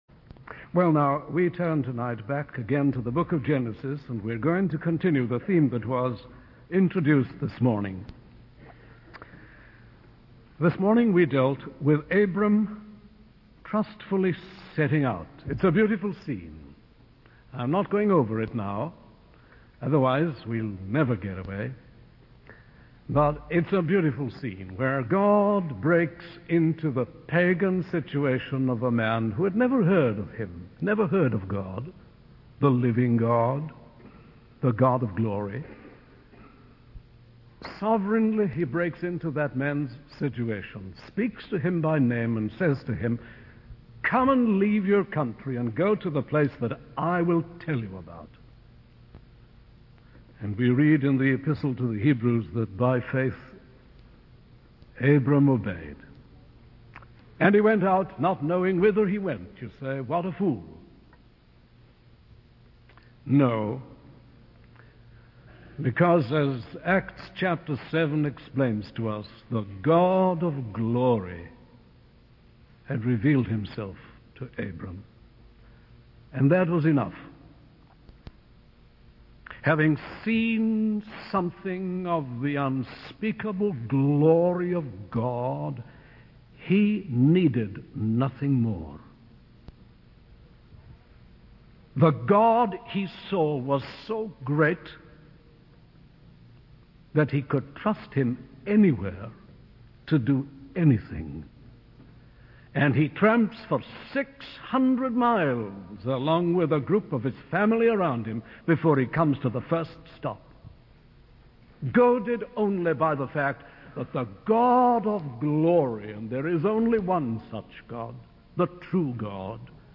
In this sermon, the preacher emphasizes the importance of taking God's promises seriously. He uses the example of Abram, who initially got stuck in Haran instead of reaching the promised land. The preacher highlights that many people start their spiritual journey but fail to reach their destination due to distractions and temptations along the way.